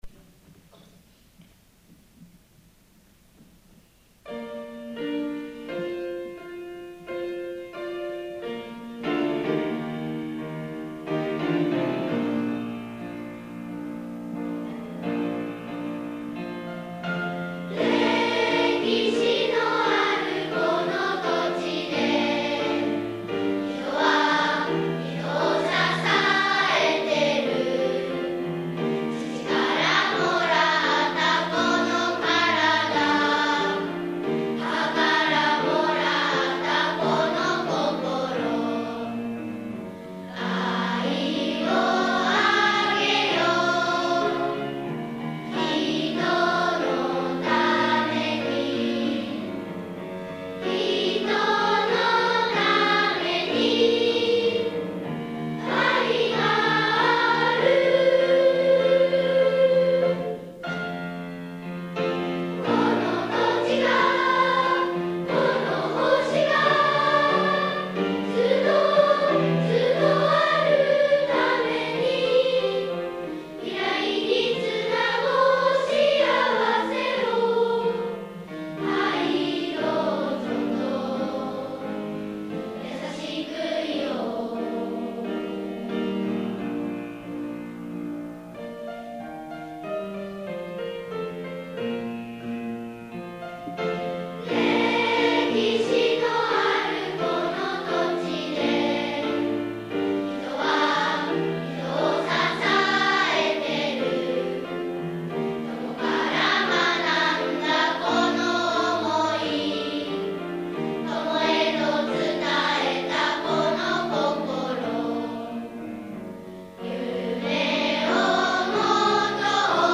↑こちらは、現在の6年生が３年生の時に録音したものです。